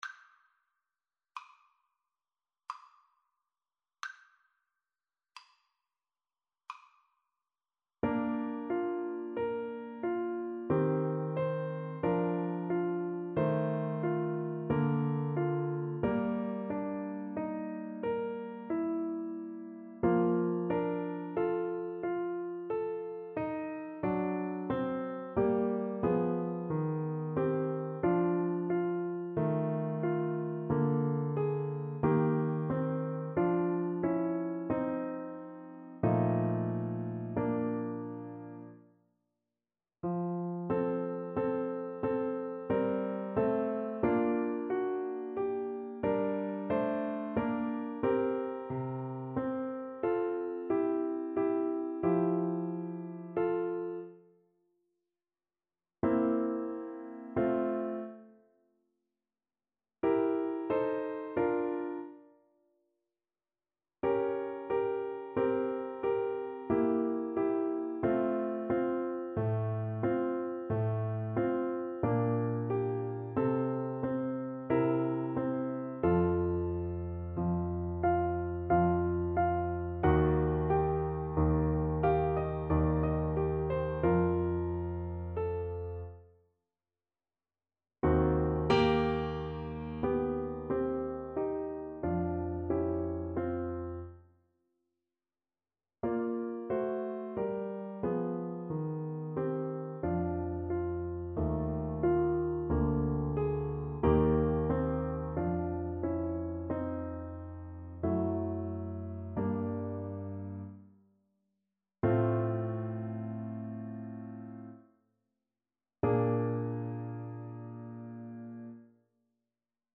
3/4 (View more 3/4 Music)
Adagio =45
Classical (View more Classical Saxophone Music)